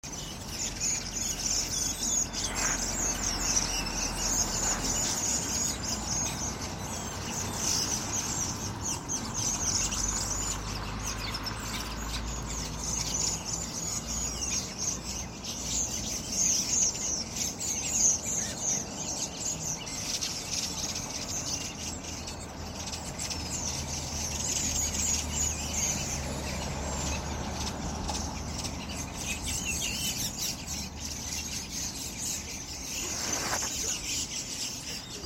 Þeir virðast æstir og skríkja hátt. Þegar þeir eru komnir í náttstað halda þeir skríkjunum áfram um stund en koma sér svo í ró.
Þrestir og starar í náttstað:
Hljóðupptakan er til dæmis fengin í litlum trjálundi við Neskirkju síðla vetrar 2024.